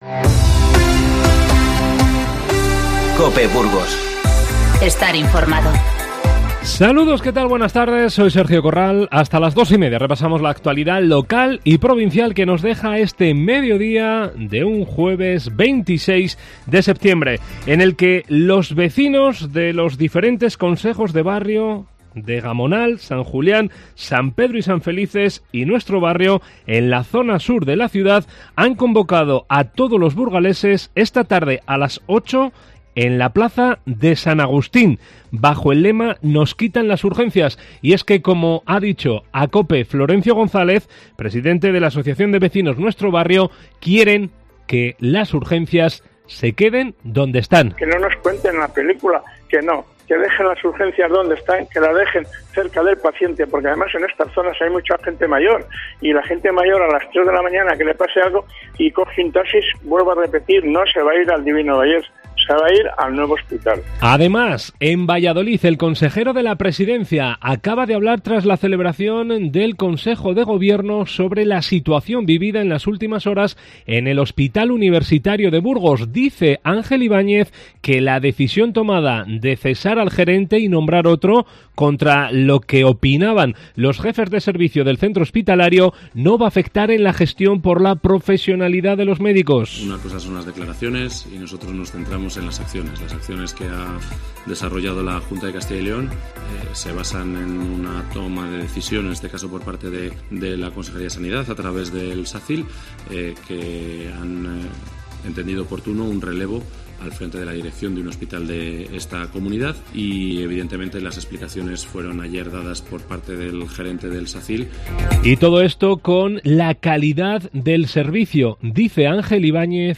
INFORMATIVO Mediodía 26-9-19.